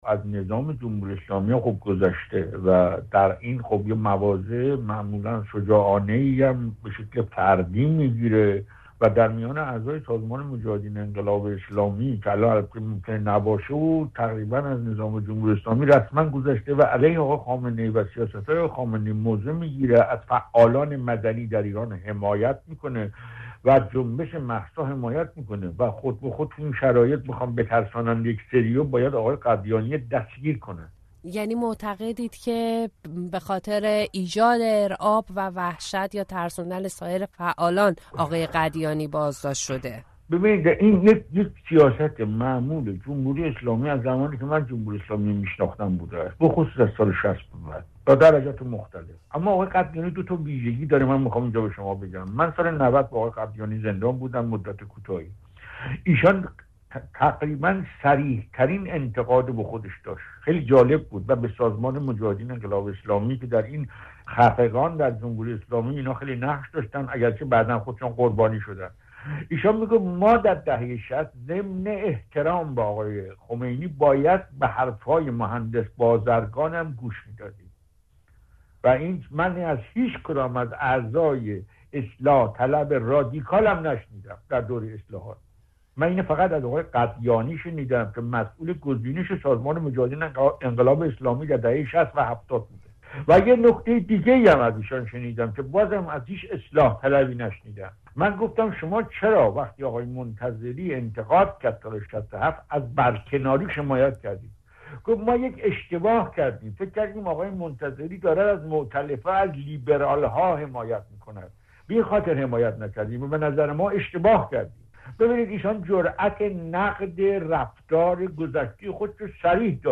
ابوالفضل قدیانی، فعال سیاسی مخالف علی خامنه‌ای رهبر جمهوری اسلامی، روز ۹ مهر بازداشت شد. با تقی رحمانی،‌ فعال سیاسی و تحلیلگر مسائل ایران، درباره دلایل بازداشت او گفتگو کردیم.